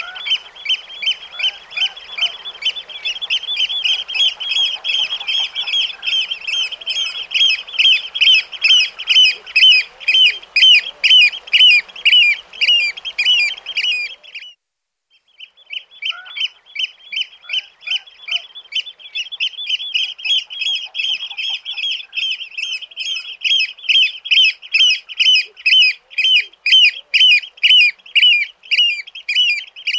Huîtrier pie - Mes zoazos
Il fréquente les rivages sablonneux et rocheux, où il se nourrit principalement de mollusques, de vers marins et de crustacés. Son cri sonore et perçant est souvent entendu le long des côtes.
huitrier-pie.mp3